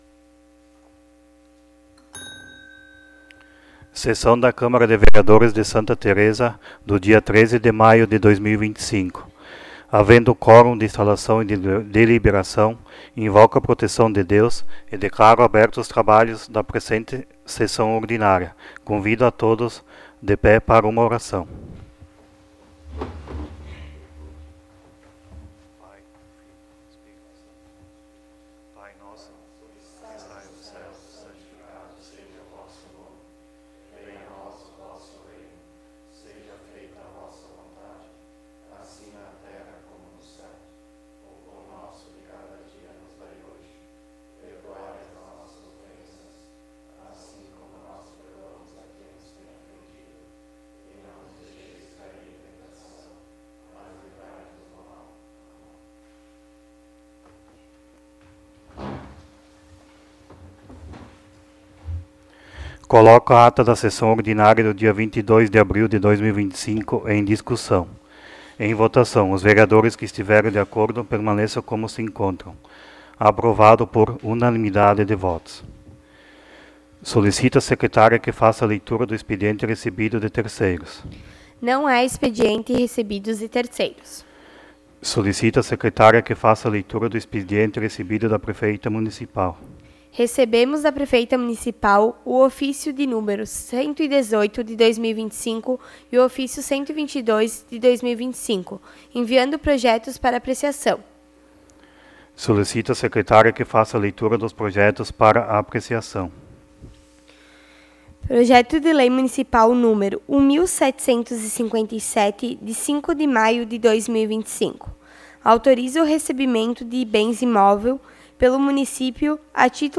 07° Sessão Ordinária de 2025